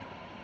tv-channel-change-v1.wav